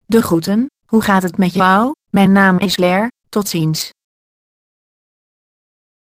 Texte de d�monstration lu par Claire (Nuance RealSpeak; distribu� sur le site de Nextup Technology; femme; hollandais)